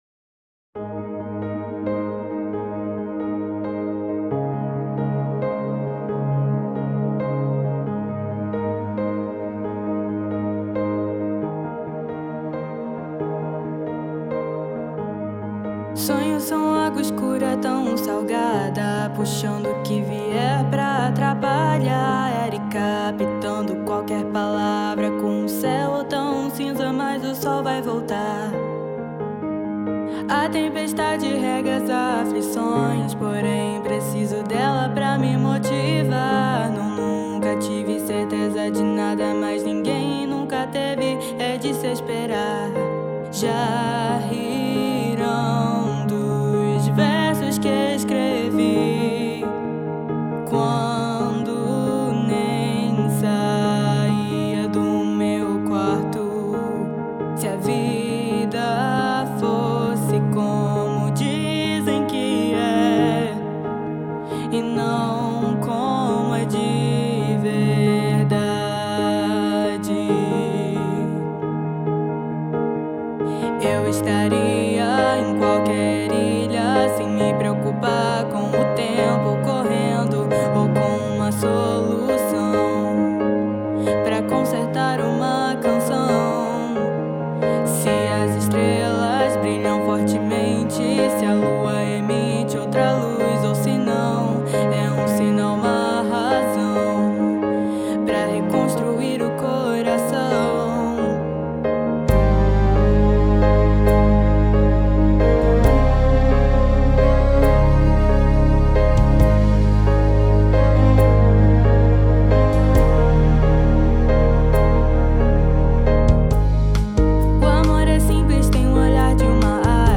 A VOZ DA NOVA MPB